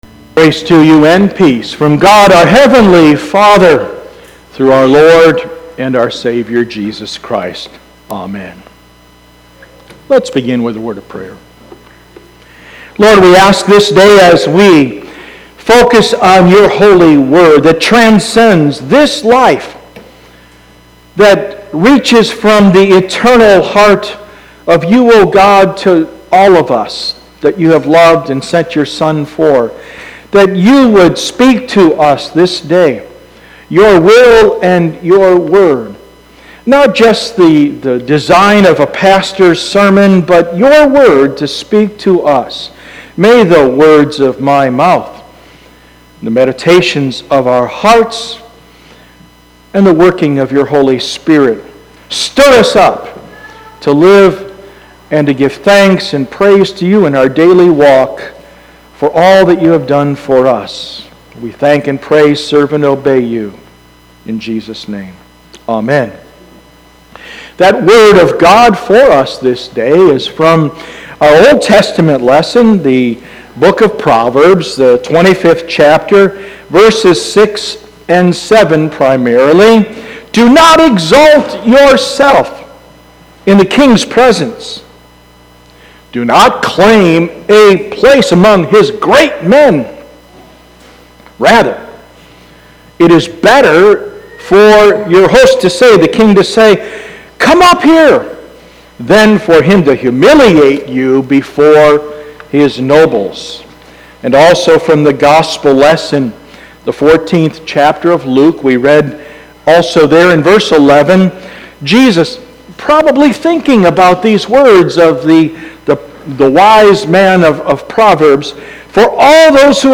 Sermon 9-1-19
Sermon-9_1_19.mp3